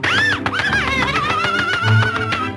Sound Effects
Disney Smacking And Gobble Sound